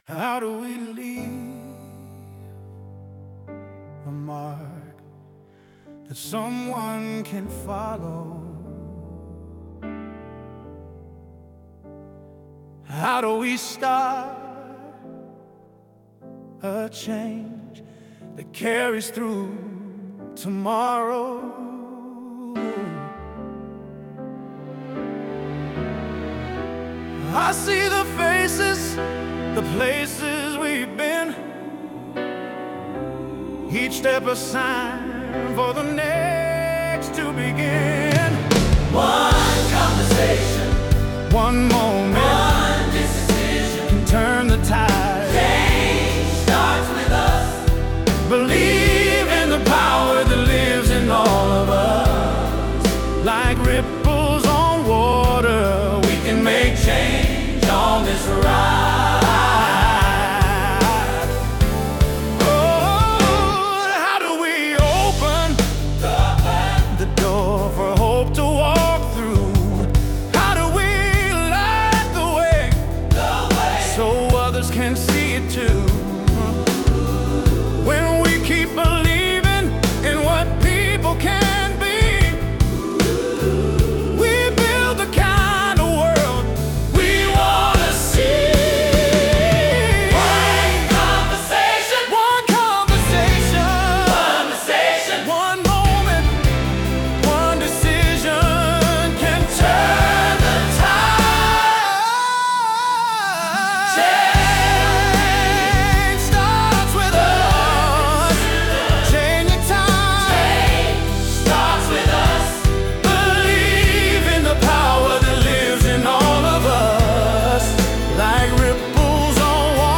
It’s a gentle reminder that change often starts small, through the choices we make and the conversations we share.
Song composed by CMA: One Conversation